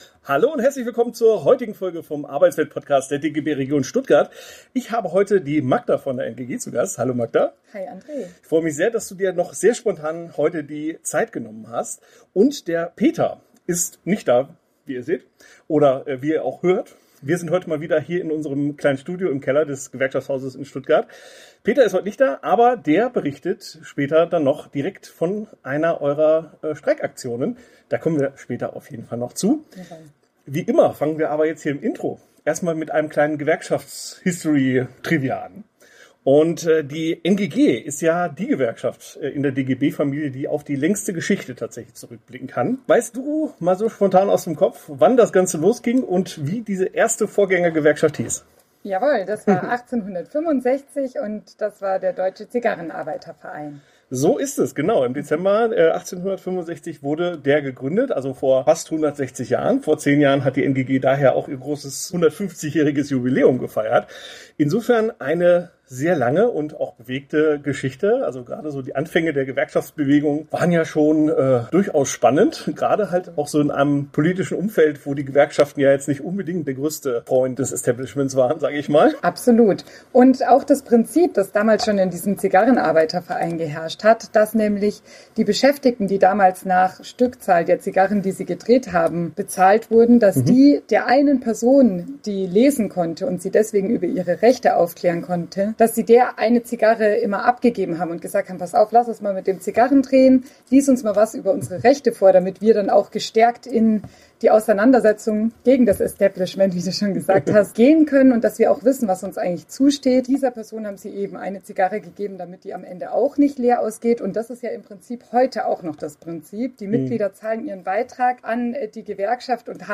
20:27 Interviews beim Streik in Murr